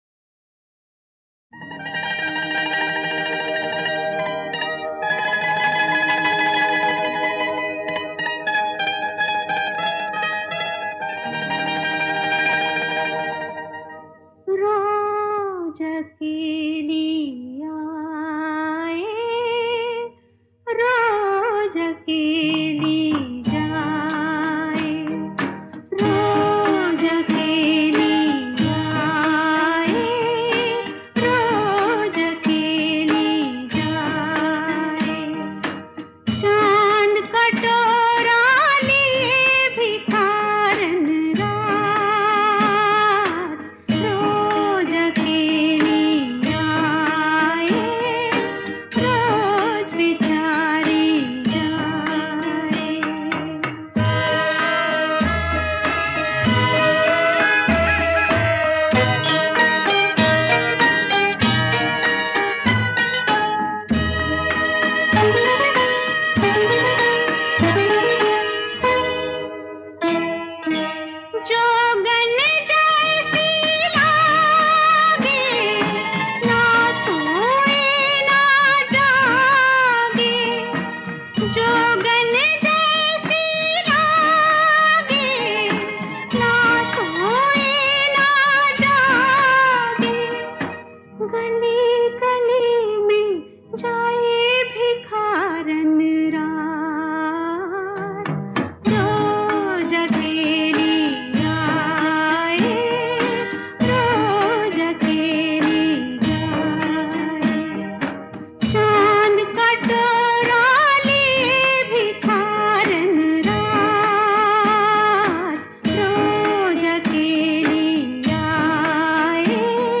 First a Hindi song